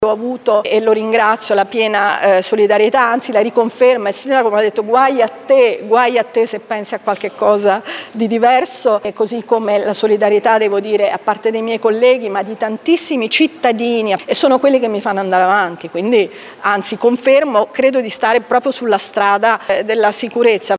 Intanto l’opposizione chiede le dimissioni dell’assessore  per gli scarsi risultati ottenuti nel primo anno di mandato in termini di sicurezza del territorio. La risposta di Camporota: